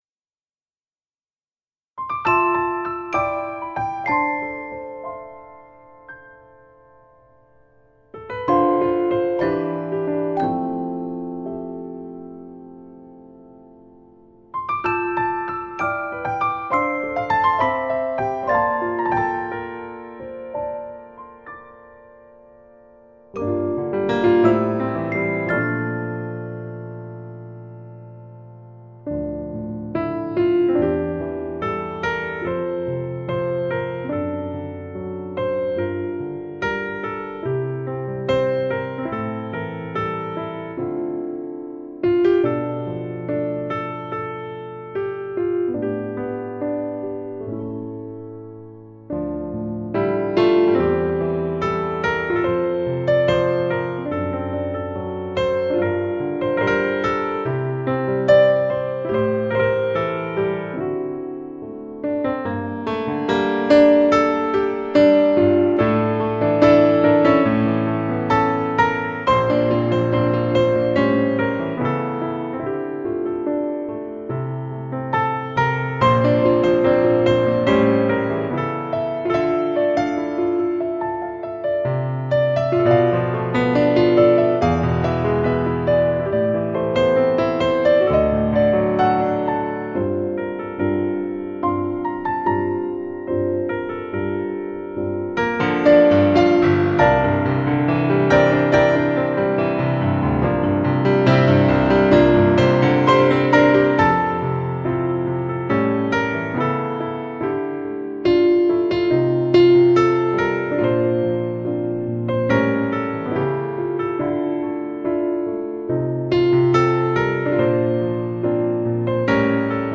全曲寧靜又不失張力，澎湃又帶著希望，峰迴路轉後 又看見另一片天空，餘韻無窮。